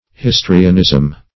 Search Result for " histrionism" : The Collaborative International Dictionary of English v.0.48: Histrionism \His"tri*o*nism\, n. Theatrical representation; acting; affectation.